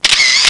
Camera Sound Effect
camera-1.mp3